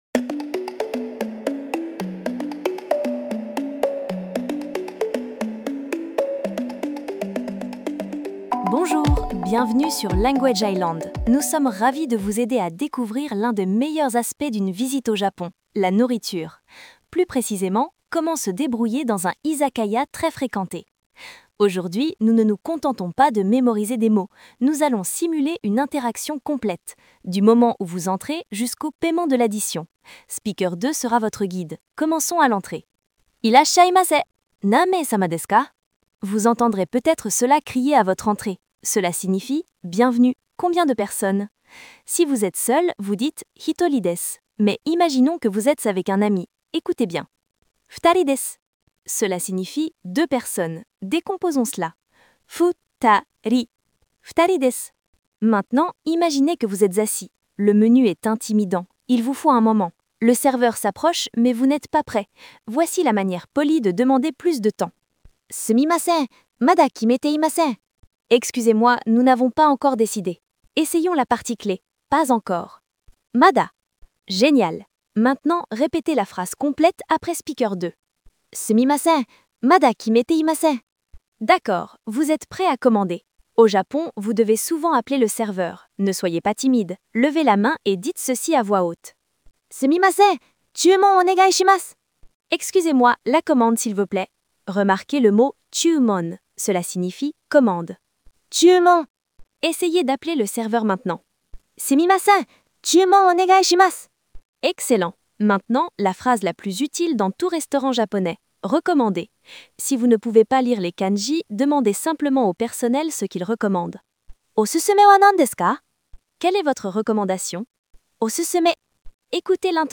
JapaneseLearn JapaneseJapanese languageJapanese phrasesLanguage IslandFrench narrationFood & DiningIzakayaIrasshaimaseNanmei-samaFutari